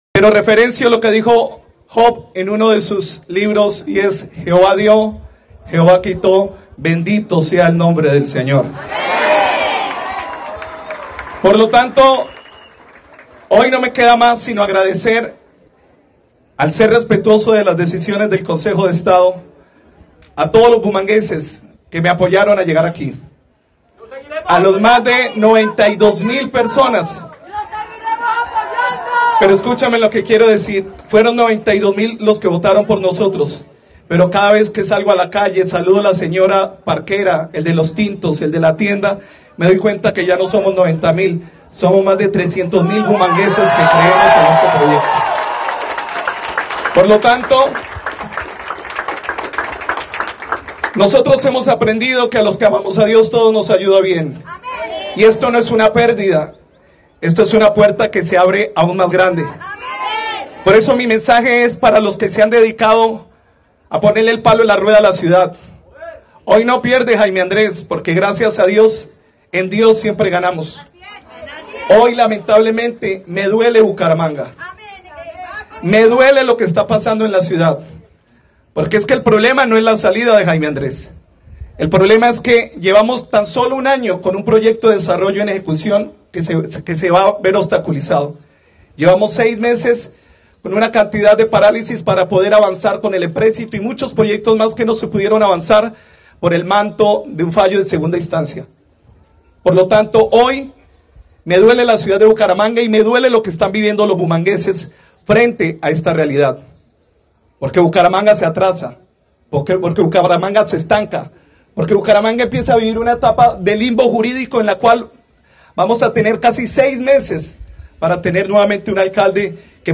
Tras el fallo del Consejo de Estado que anula la elección como alcalde, el mandatario se dirigió ante el grupo de personas que se reunieron hoy frente a la Alcaldía. Habló de ir hacía la Casa de Nariño.
Jaime Andrés Beltrán, alcalde de Bucaramanga